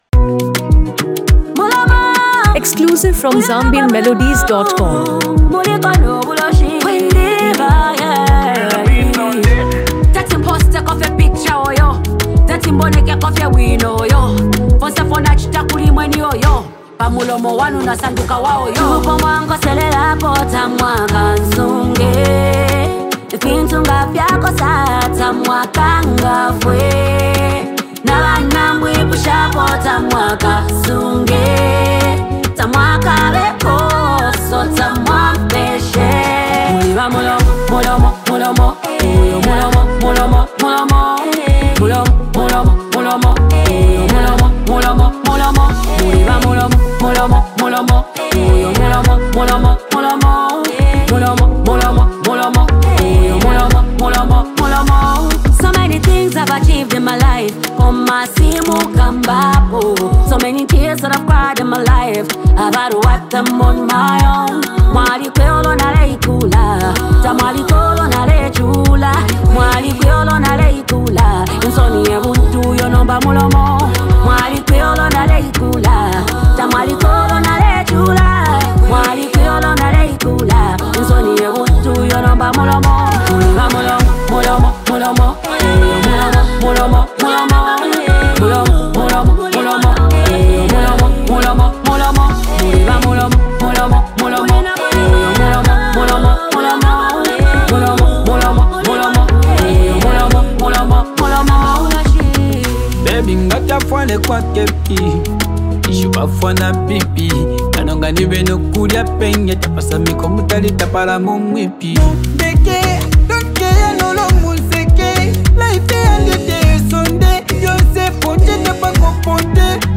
• Genre: Afro-Pop / Zambian Contemporary